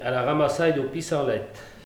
Langue Maraîchin
Patois - ambiance
Catégorie Locution